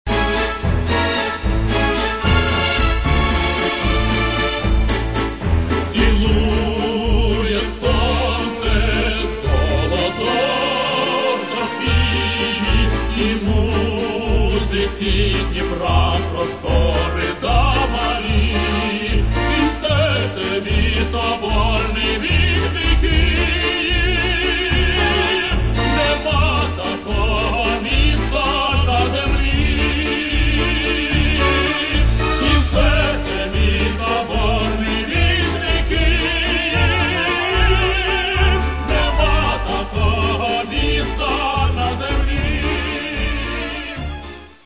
ось що таке справжні оперні голоси!!!